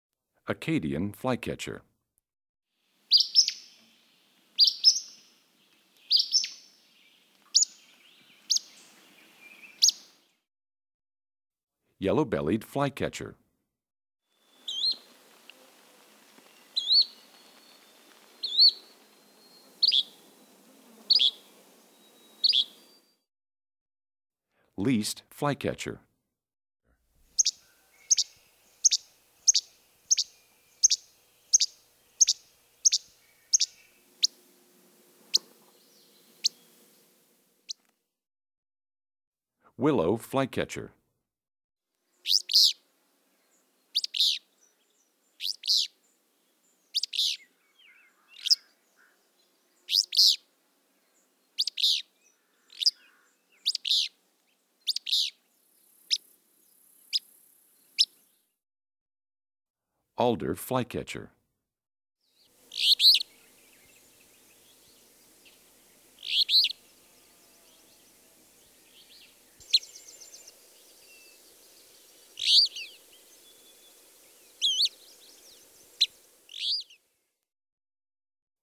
18 Acadian Flycatcher,Yellow-bellied Flycatcher,Least Flycatcher,Willow Flycatcher,Alder Flycatcher.mp3